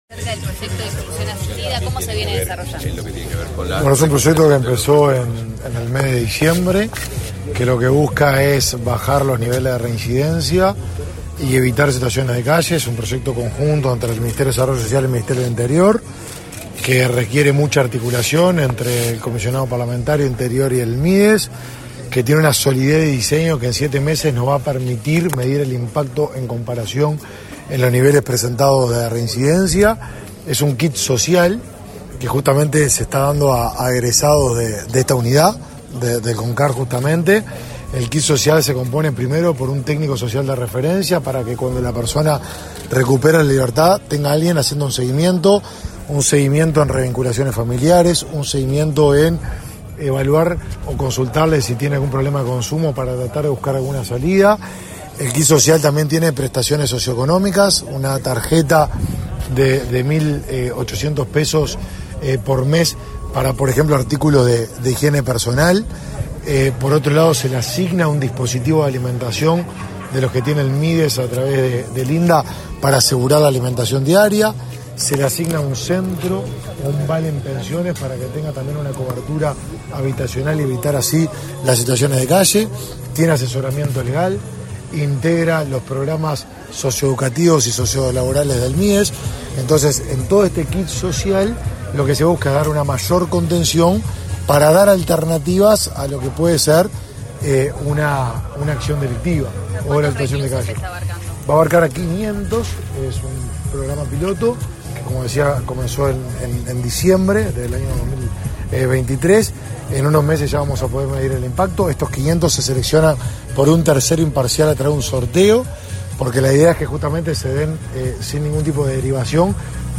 Declaraciones de prensa del ministro de Desarrollo Social, Martín Lema
Tras el evento, el ministro de Desarrollo Social, Martín Lema, efectuó declaraciones a la prensa.